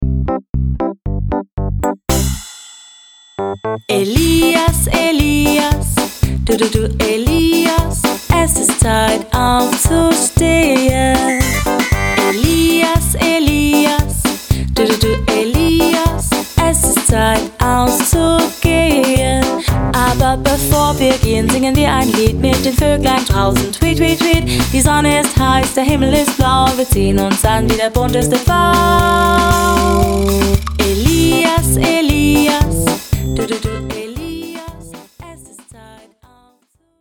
Personalisierte Kinderlieder für den ganzen Tag.
Natürlich mit guter Laune.